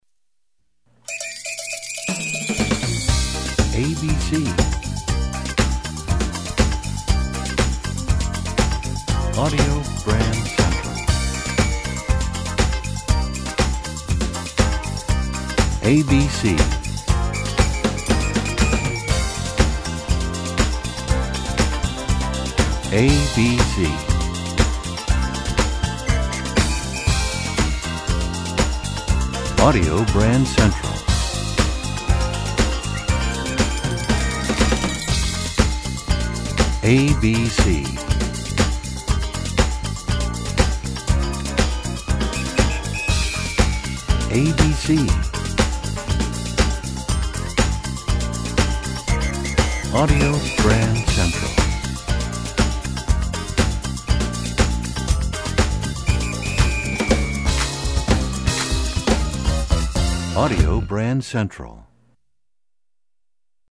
Genre: Theme Music.